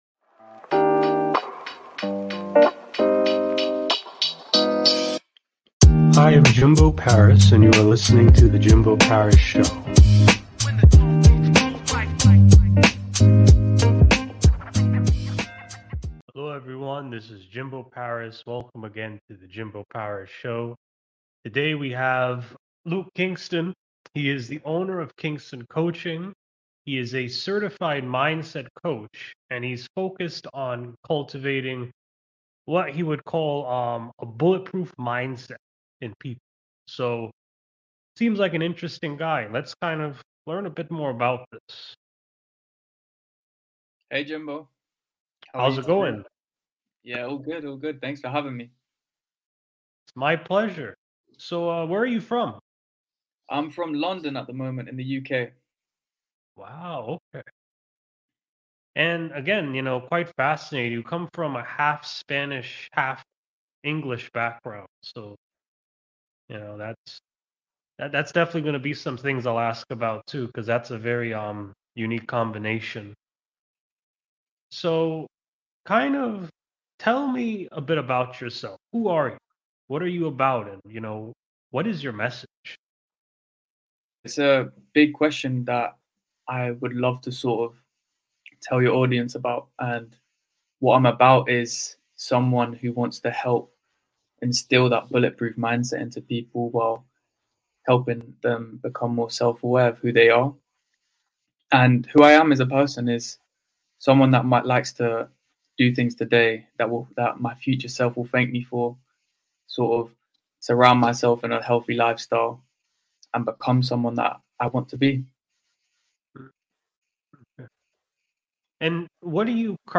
► Watch Live Interview Episode #121